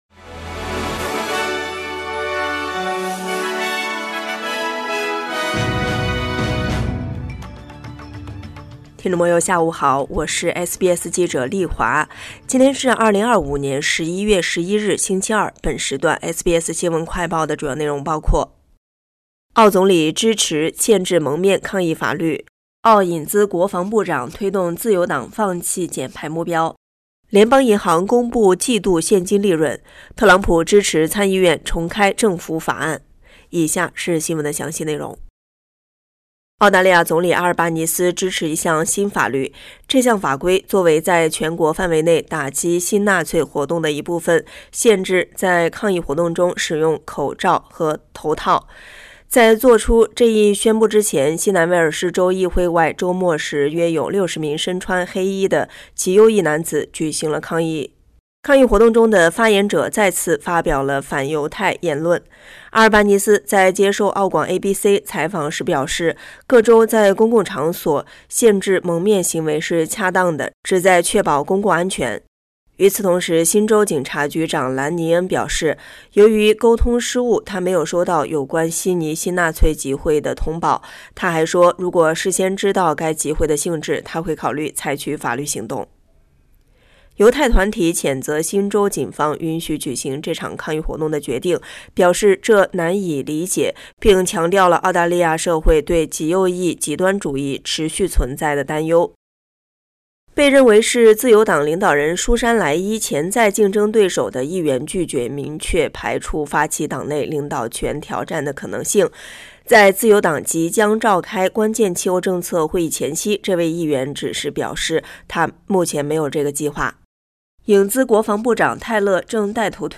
【SBS新闻快报】澳总理支持限制蒙面抗议法律